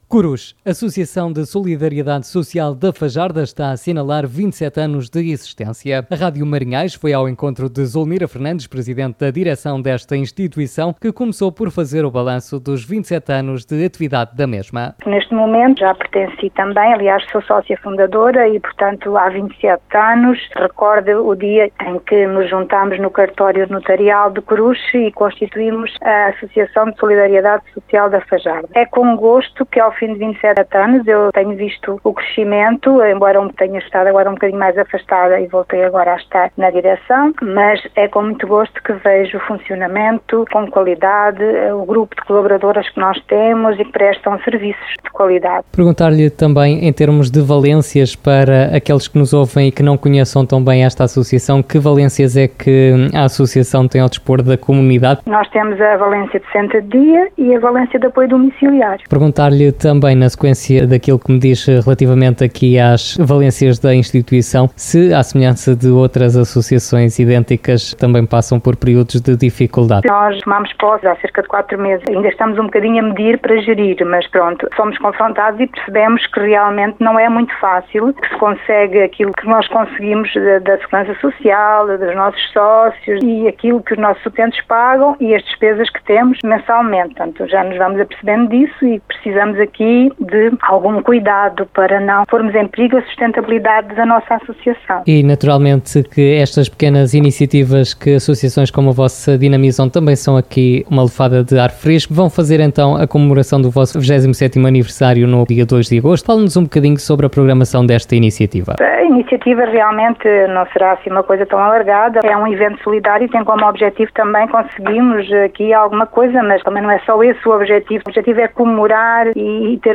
Em entrevista à Rádio Marinhais